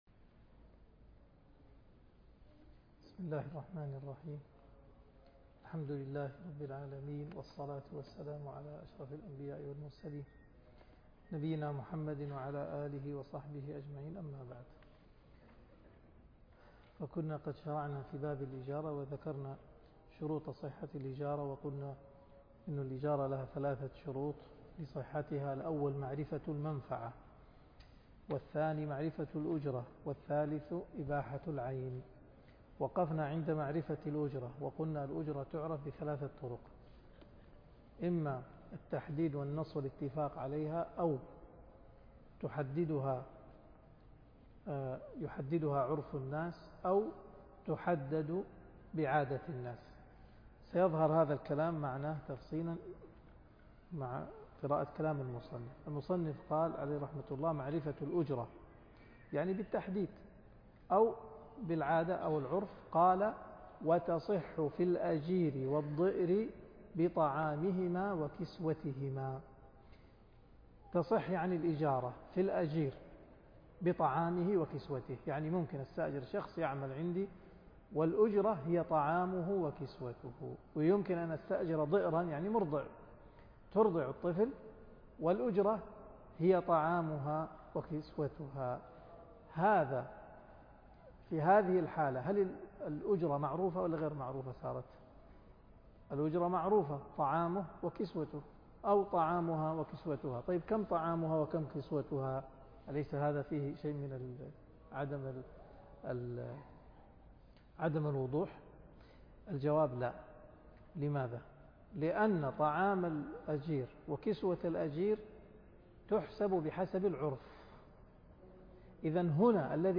الدرس (57) من قوله ولا يشترط خلط المالين إلى قوله في باب الإجارة – معرفة المنفعة كسكنى دار